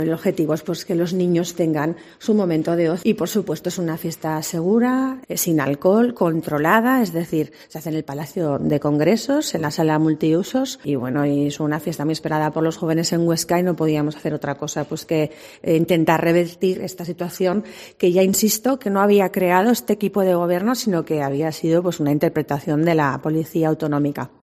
La alcaldesa de Huesca, Lorena Orduna explica lo sucedido